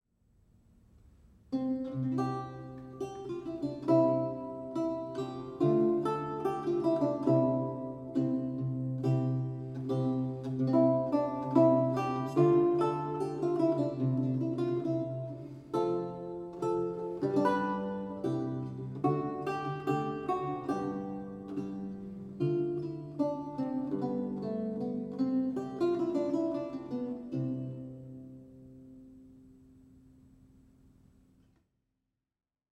Audio recording of a lute piece
a 16th century lute music piece